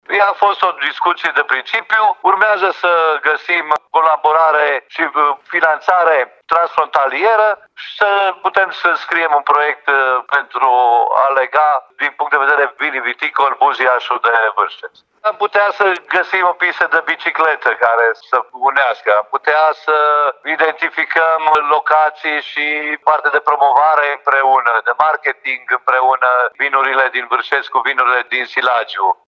Primarul localității Buziaș, Sorin Munteanu, spune că de pe Dealurile din Silagiu s-ar putea ajunge chiar cu bicicleta pe cele de dincolo de graniţă, după cum intenţionează edilii din Buziaș și Vârșeț.